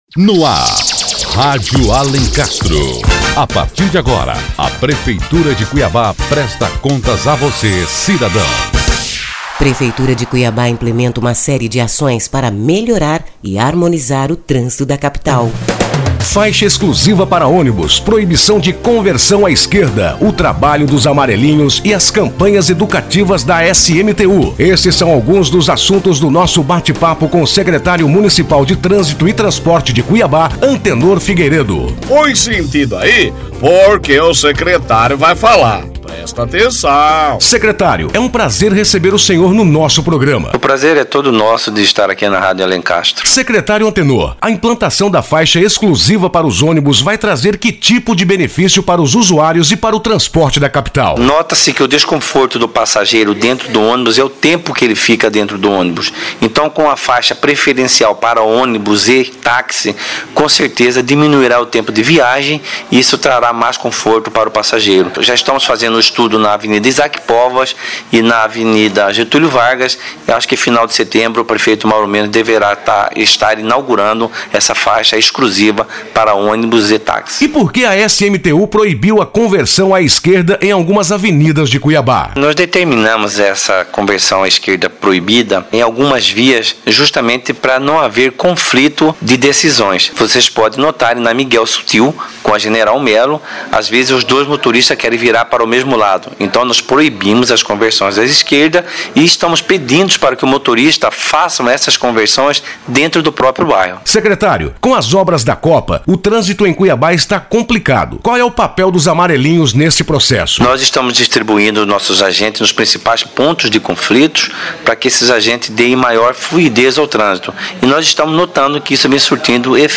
Bate-papo com o Secretário de Trânsito | Notícias - Prefeitura de Cuiabá